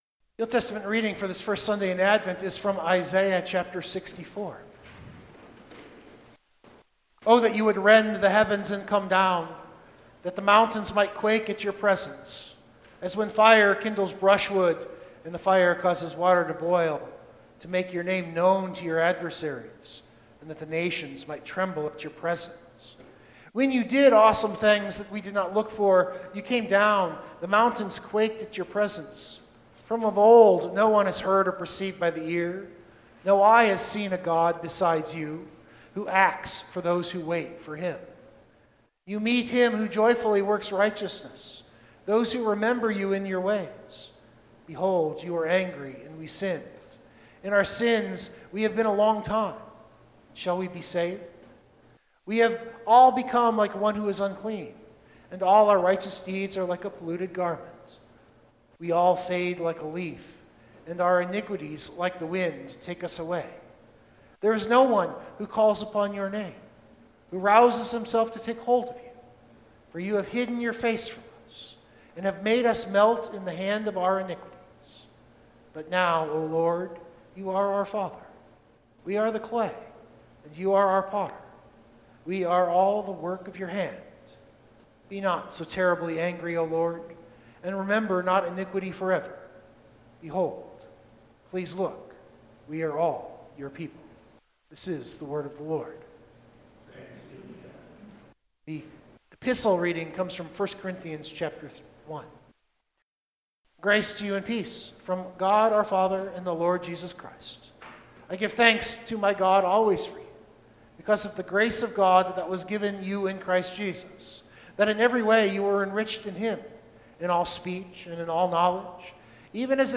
Recording Note: Sorry, the live recording was unusable, so this is a re-recording after the fact.